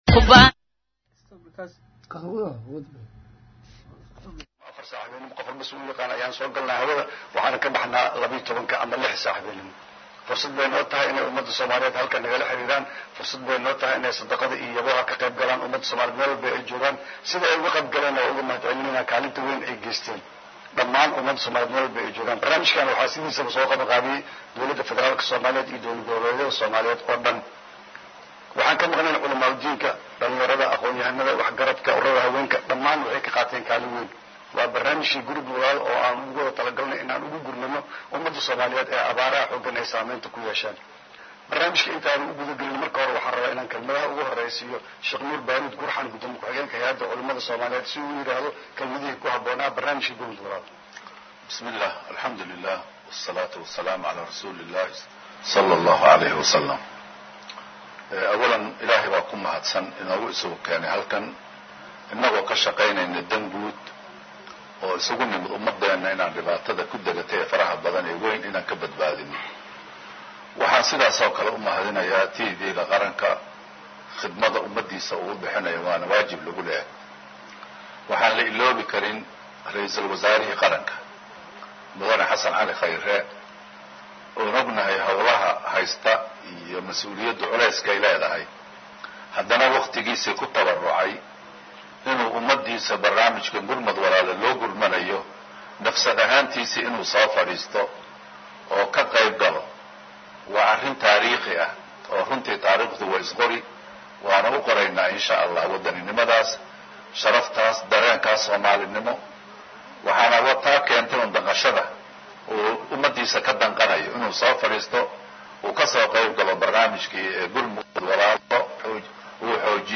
Ra’iisul Wasaarha Soomaaliya Mudane Xasan Cali Kheyre oo ka qeybgalay barnaamijka Gurmad Walaal ee ka baxa Warbaahinta Qaranka ayaa faah faahin ka bixiyay qaabka ay lacagtan ku timid.